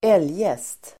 Uttal: ['el:jest]